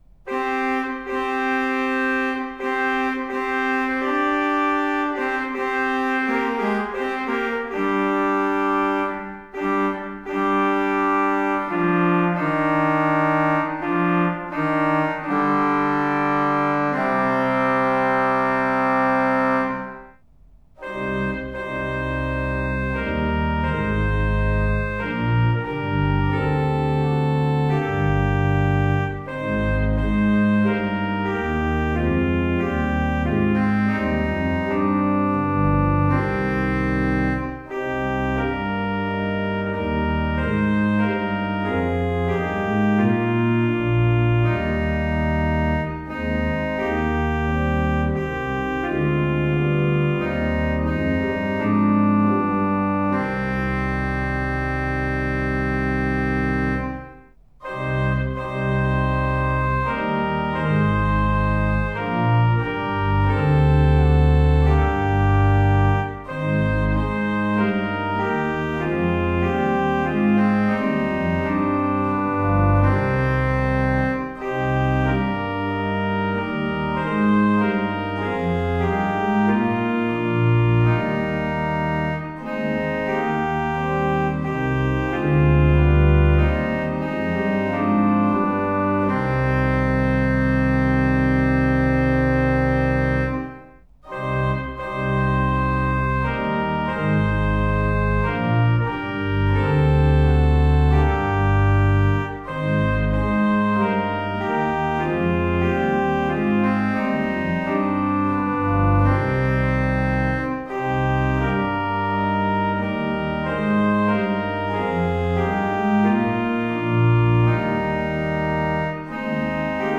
Audio Recordings (Organ)
WS760-midquality-mono.mp3